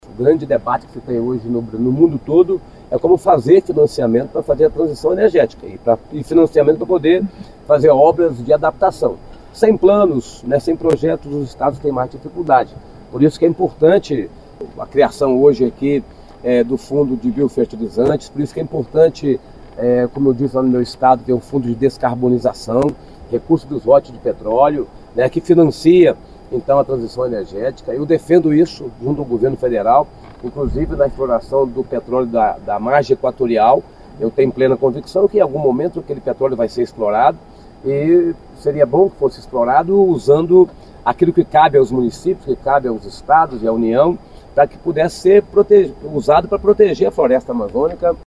No Espírito Santo, segundo o governador Renato Casagrande, a estratégia será apoiar setores produtivos regionais com linhas de crédito e incentivos tributários.